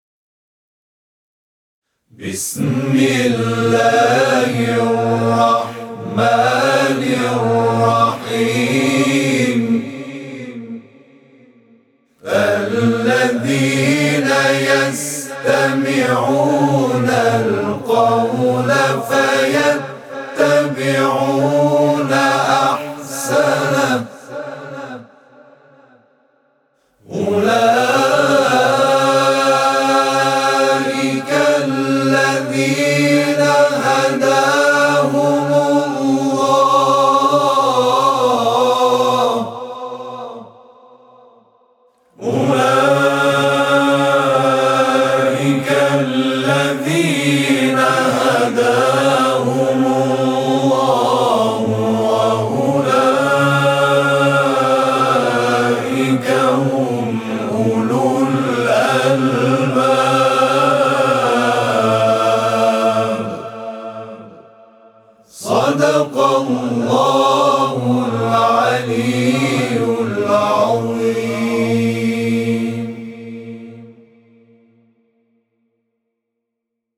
صوت همخوانی آیه 18 سوره «زمر» از سوی گروه تواشیح «محمد رسول‌الله(ص)»